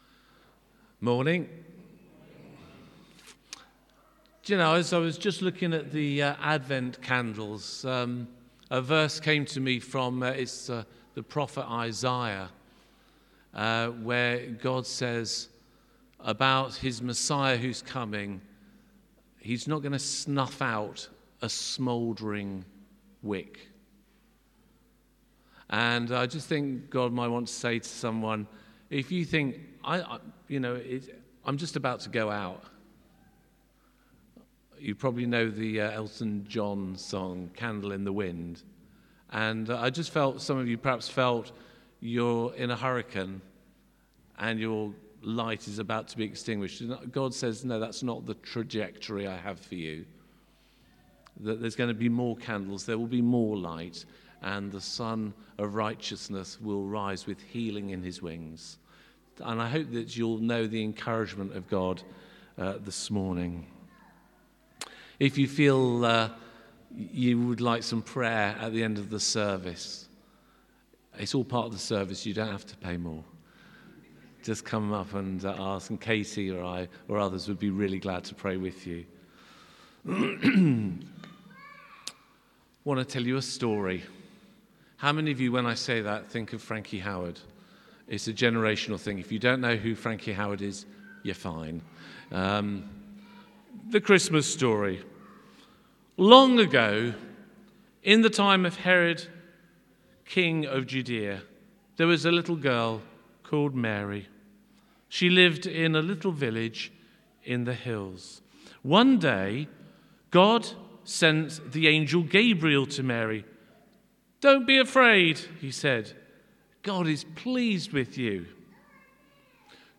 Sunday-5th-December-sermon.mp3